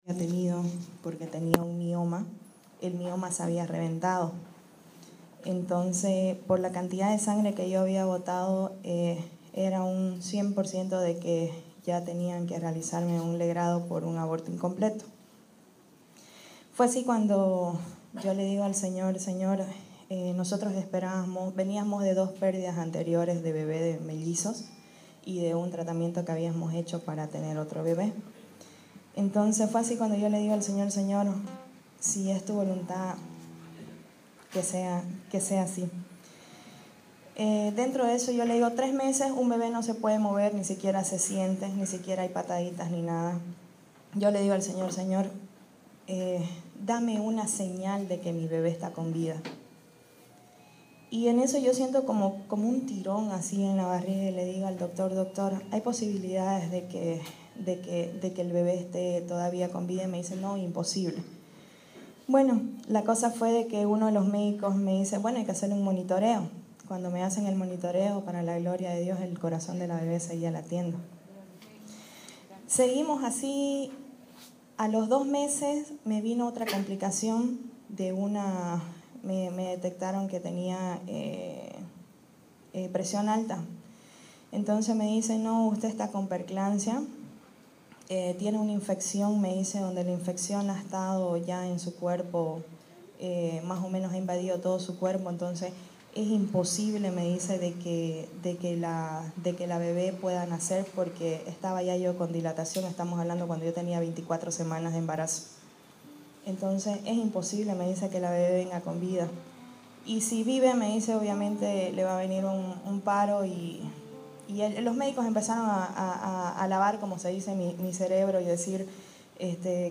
La Esperanza de los Padres - Testimonio